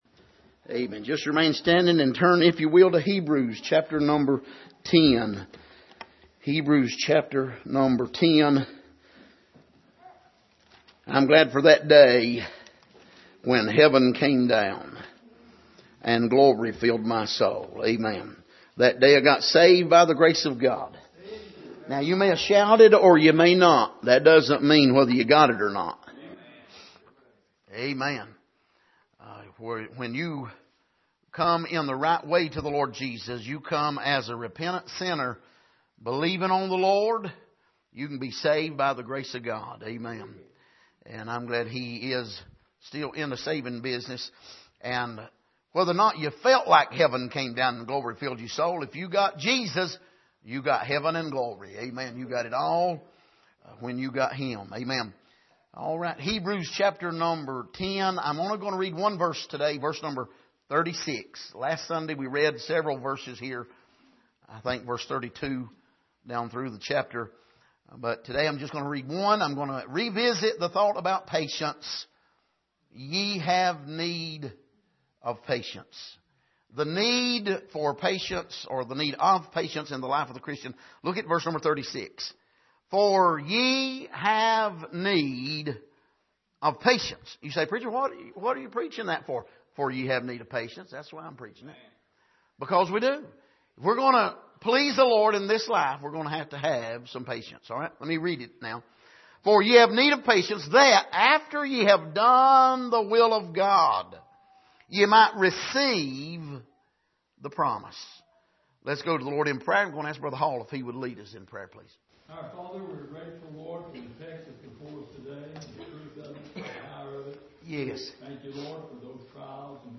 Passage: Hebrews 10:32-39 Service: Sunday Morning